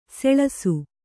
♪ seḷasu